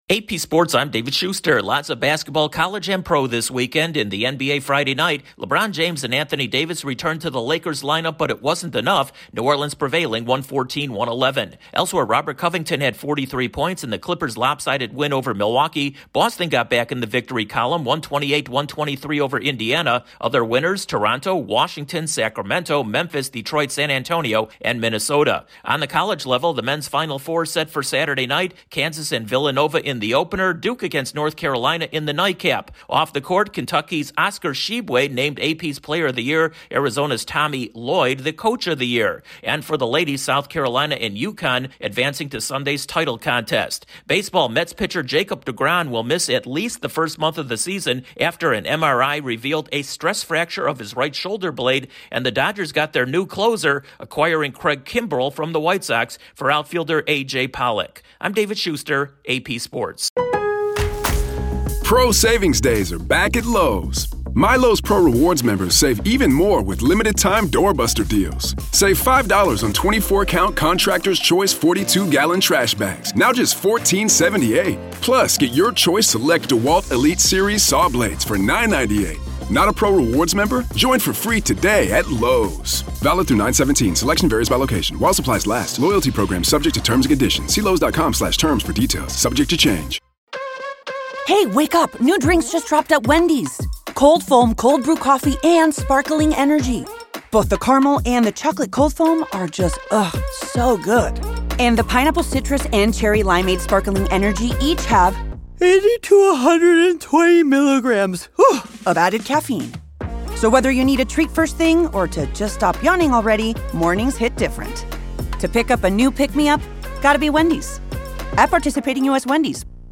Update on the latest sports